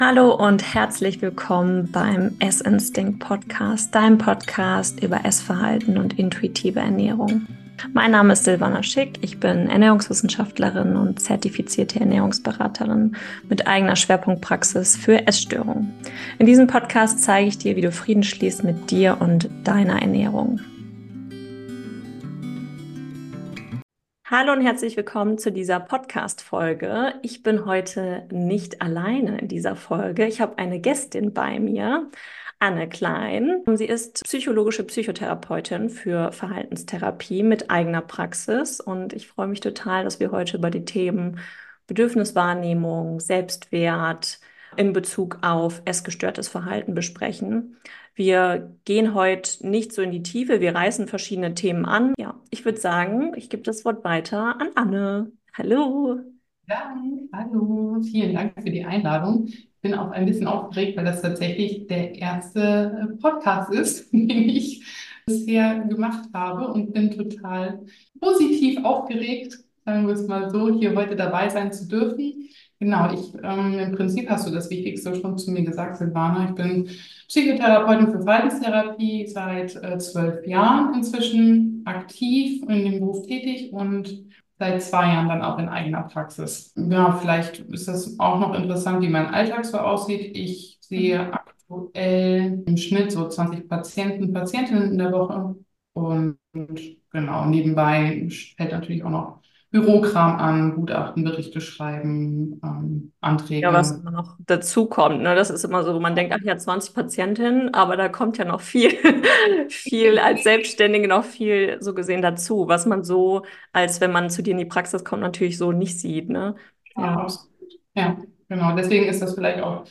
Zwei Therapeutinnen sprechen über Tools für Selbstwertwahrnehmung und die Auflösung von Glaubenssätzen (Selbstannahmen) im Bezug auf den eigenen Selbstwert.